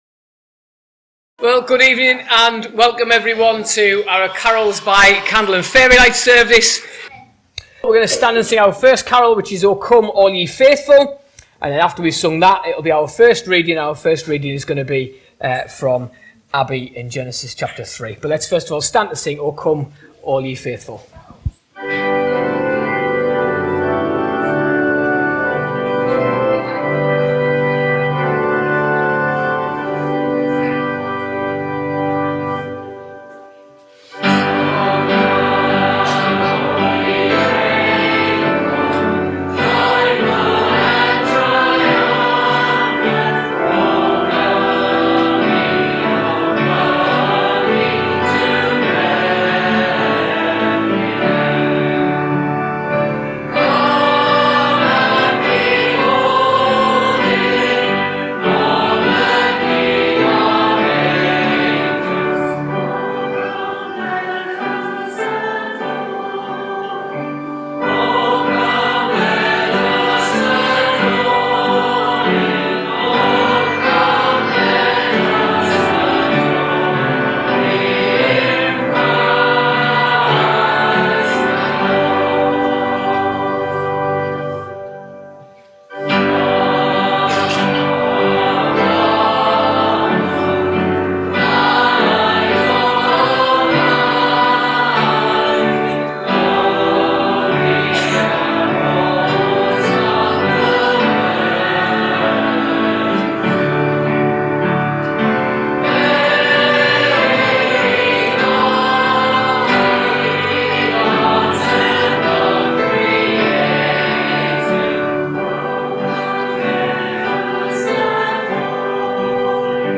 2025 ~ Christmas Eve ~Carols by candlelight. – Hardwick Baptist Church